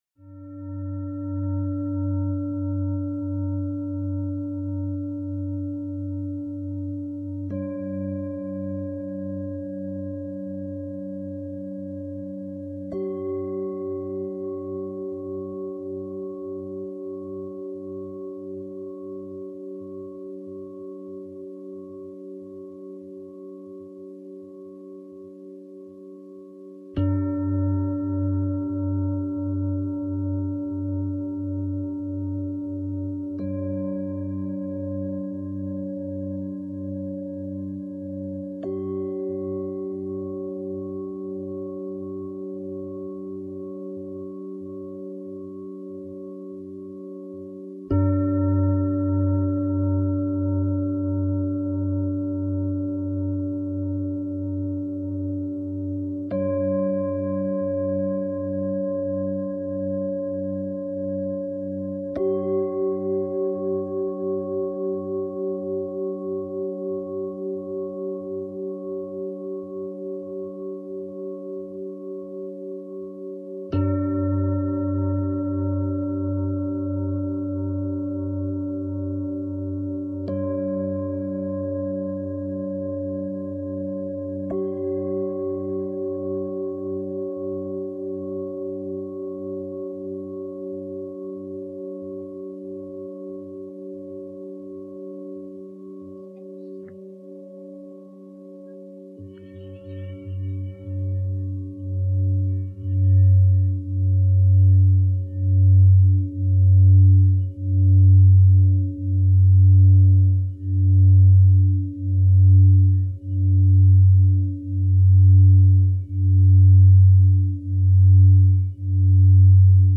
Sound Healing